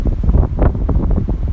近距离录到的风扇风声
描述：近距离录到的风扇风声，听起来有点像风暴，但是没有变化。
标签： 风扇 风暴
声道立体声